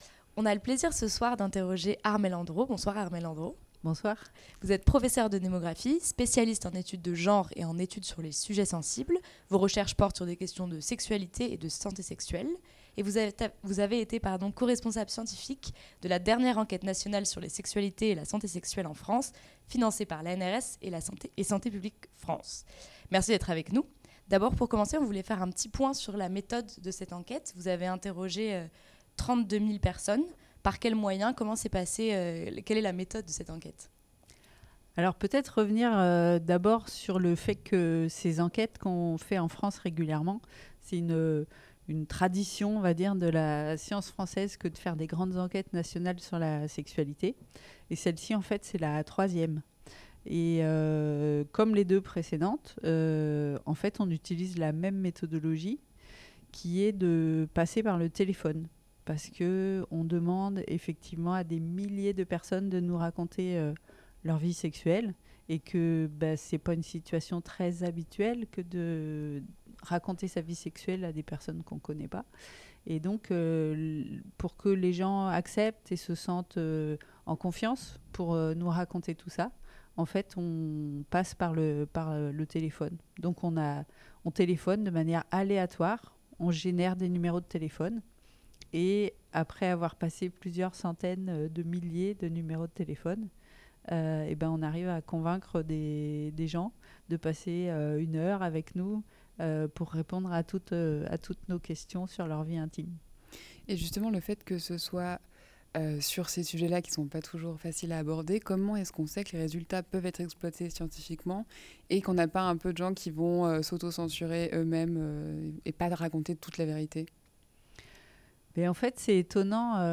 L'interview est à retrouver ici en version longue !